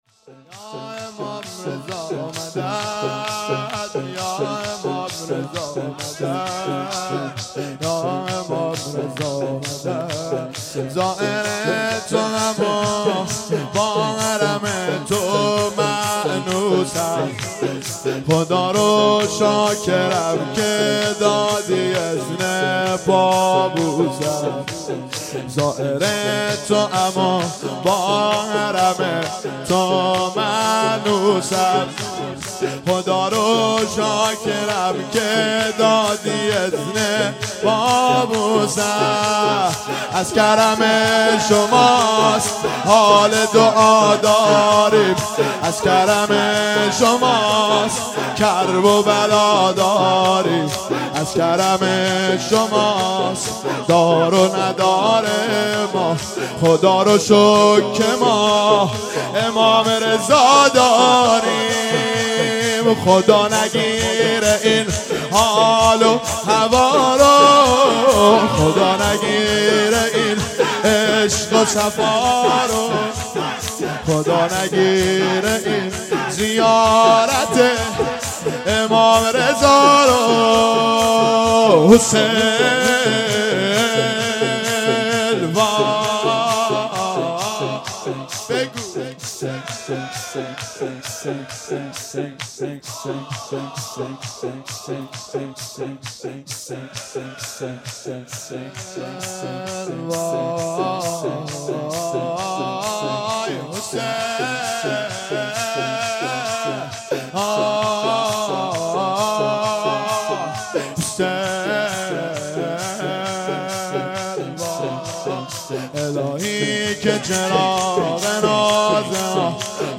مجموعه صوتی عزاداری شام شهادت امام رضا (علیه السلام)
شور اول / پیشنهاد  دانلود